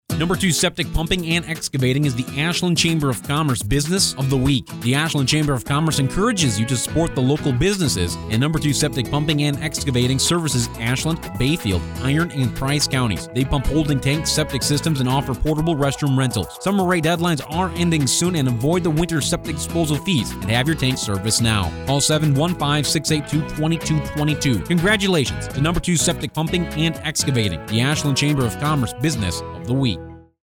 Ashland Chamber’s Business of the Week for September 3: #2 Septic Pumping & Excavating, Inc. Each week the Ashland Area Chamber of Commerce highlights a business on Heartland Communications radio station WATW 1400AM. The Chamber draws a name at random from our membership and the radio station writes a 30-second ad exclusively for that business.